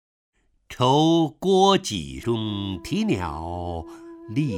首页 视听 名家朗诵欣赏 陈醇
陈醇朗诵：《酬郭给事》(（唐）王维)
ChouGuoJiShi_WangWei(ChenChun).mp3